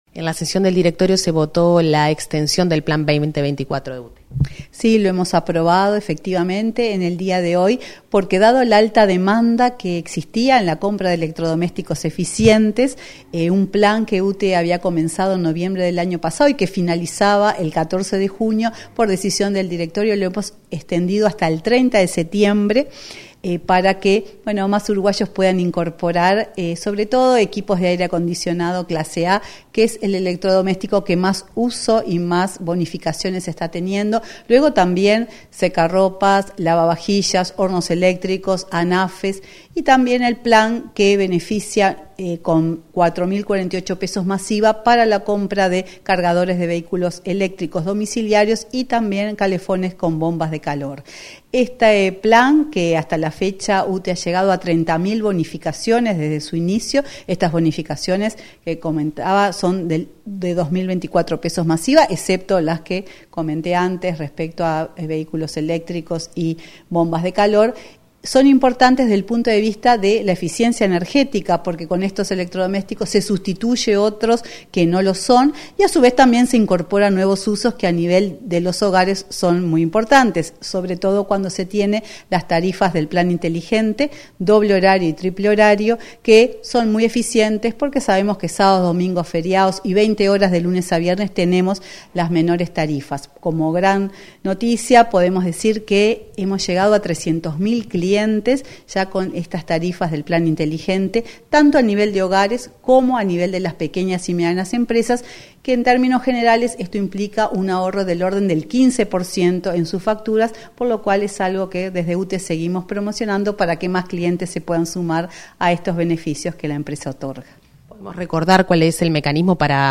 Entrevista a la presidenta de UTE, Silvia Emaldi, sobre Plan 2024